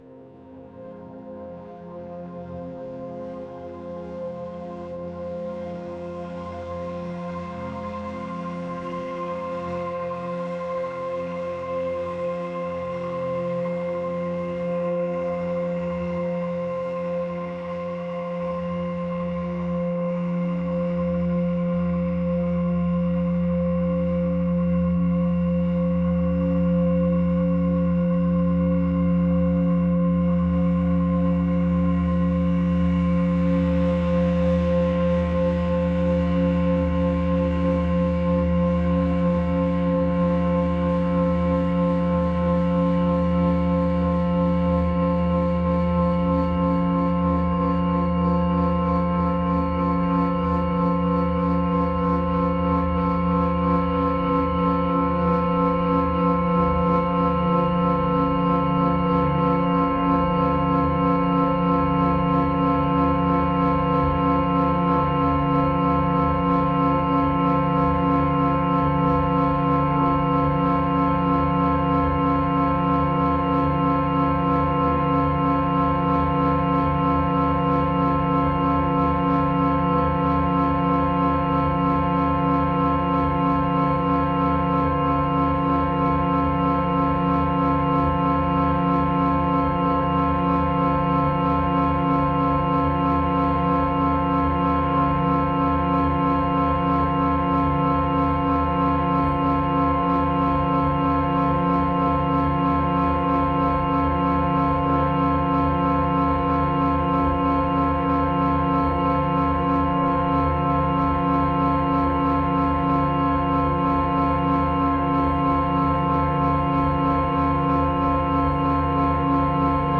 ethereal | pop | atmospheric